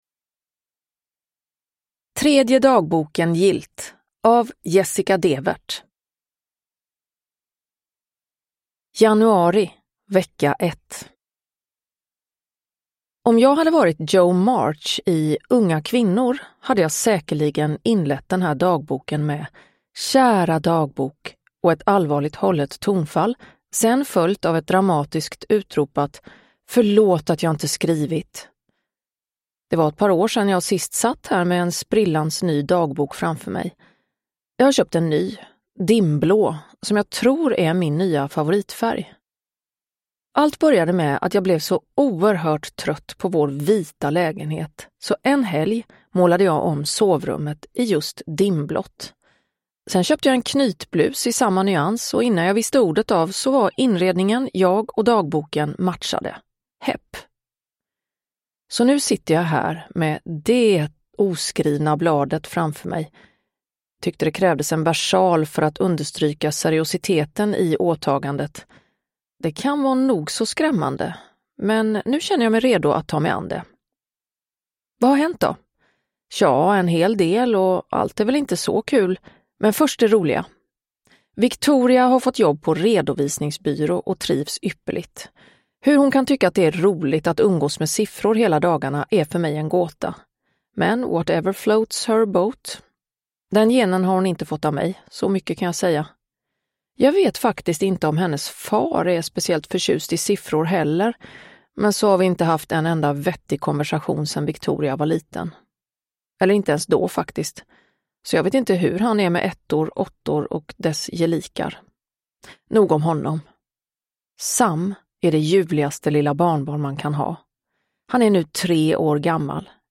Tredje dagboken gillt – Ljudbok
Uppläsare: Klara Zimmergren